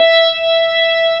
55O-ORG17-E5.wav